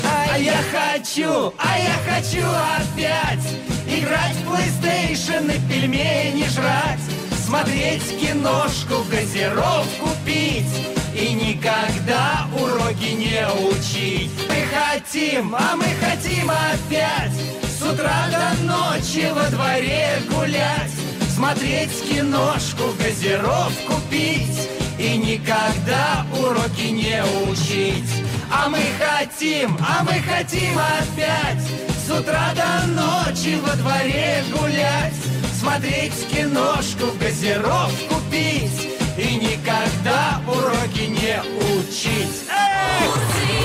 • Качество: 192, Stereo
веселые
смешные